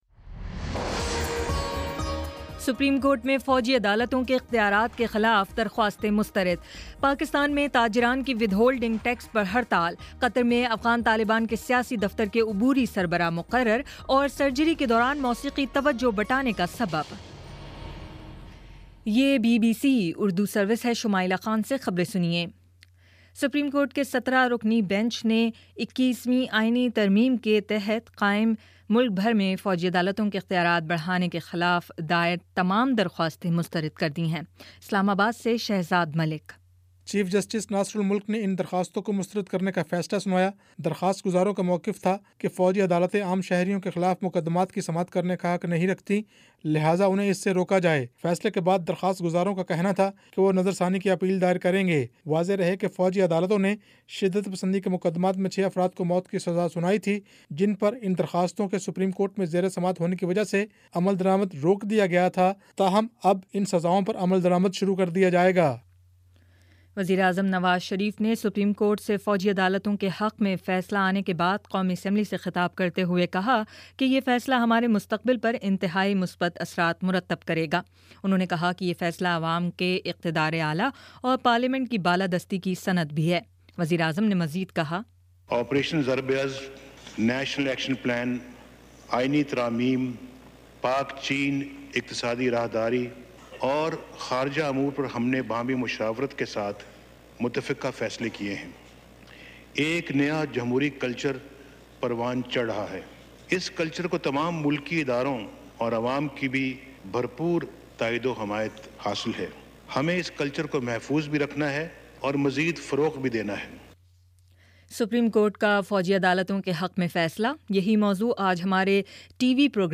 اگست 05: شام پانچ بجے کا نیوز بُلیٹن